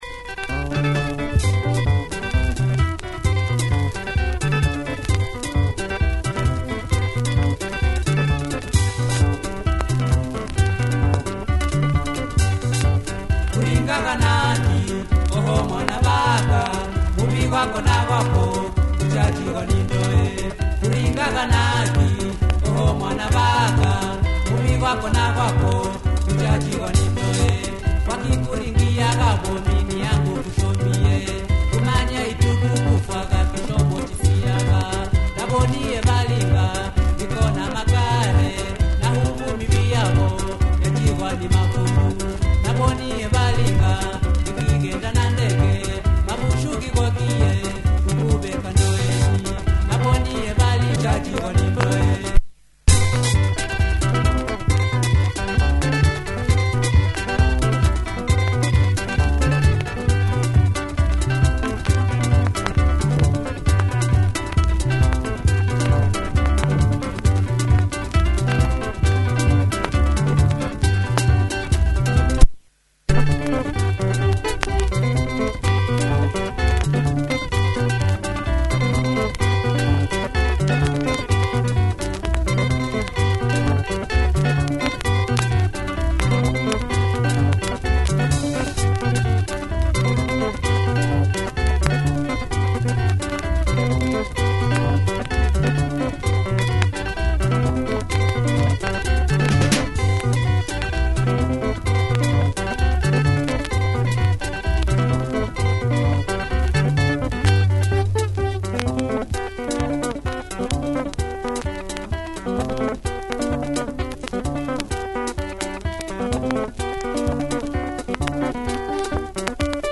and here is another great Taita track